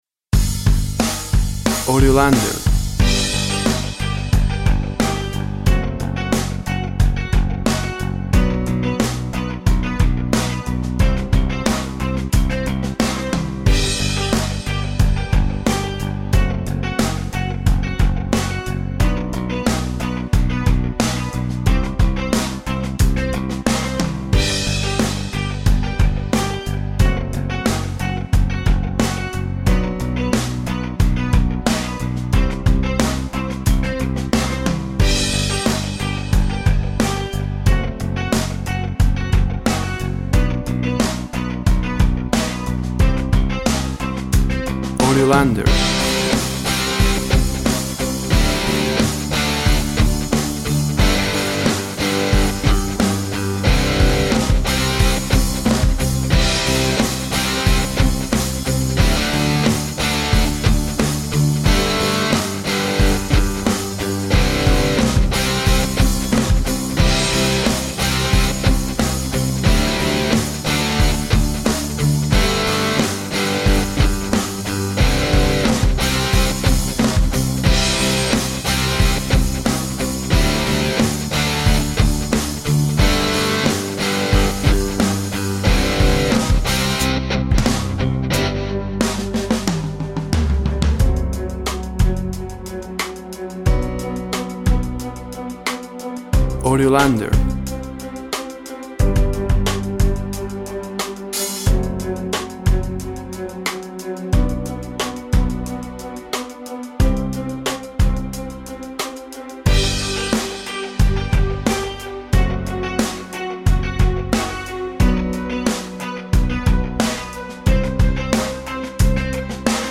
Instrumental Rock.
Tempo (BPM) 90